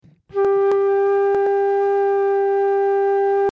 Viel schlimmer sind aber die Störgeräusche, die bei der Aufnahme entstehen. In der Monitor-Ausgabe im Kopfhörer höre ich das Mikrofonsignal klar und deutlich, wenn ich aber die Aufnahme anhalte und wiedergebe sind unangenehme Störgeräusche in der Aufnahme.
Gespielt habe ich einen durchgängigen ungestörten Flötenton.